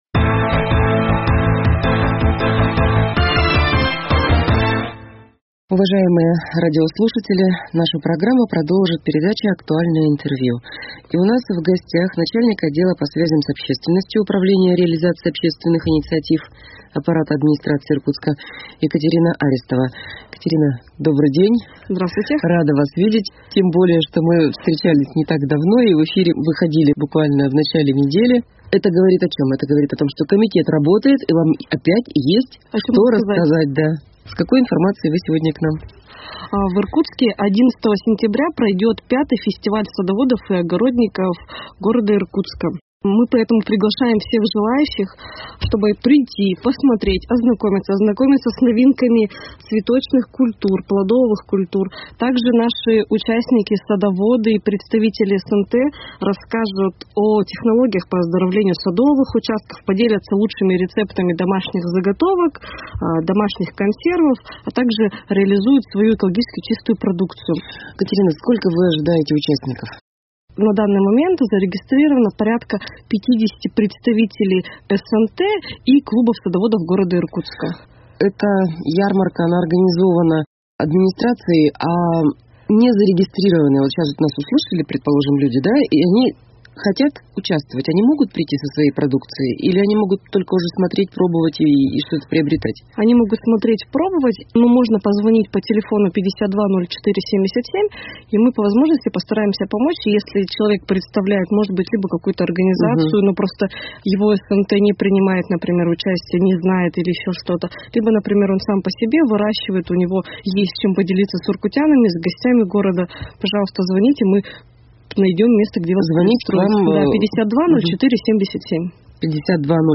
Актуальное интервью: Ярмарка садоводов 08.09.2021